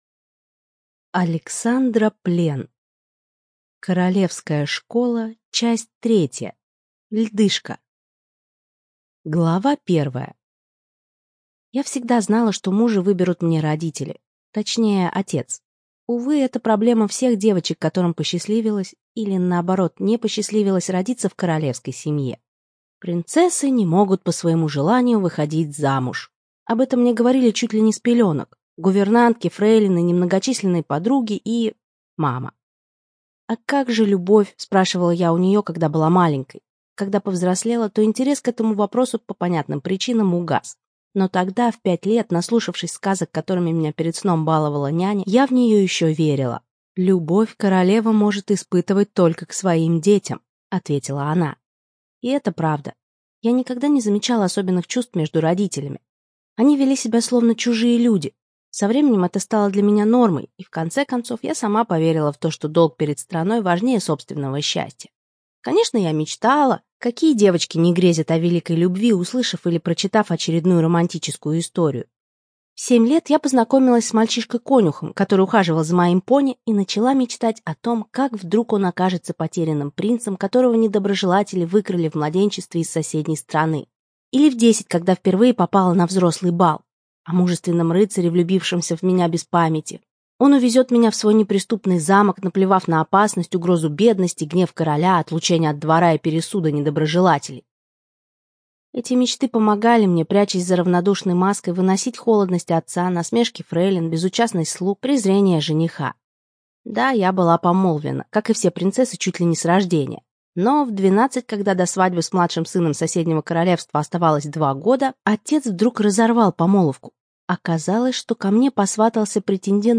ЖанрЛюбовная проза, Фэнтези